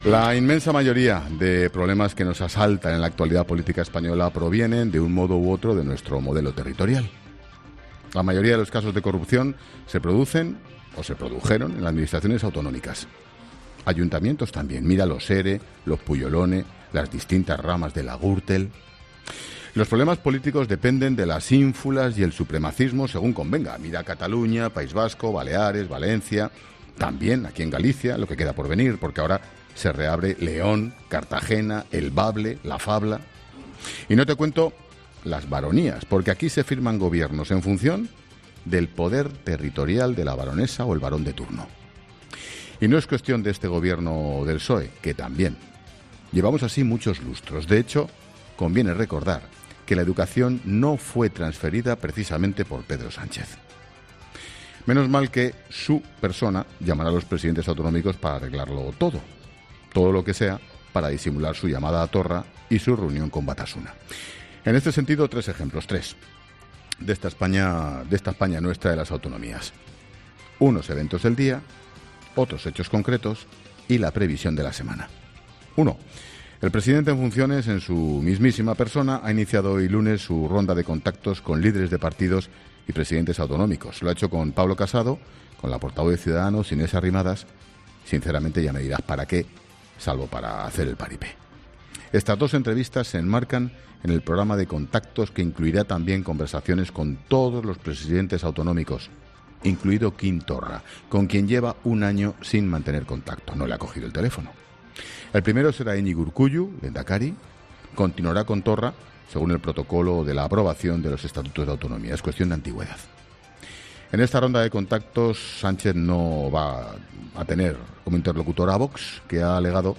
Monólogo de Expósito
El presentador de La Linterna analiza la ronda de contactos de Pedro Sánchez con líderes políticos y autonómicos para la investidura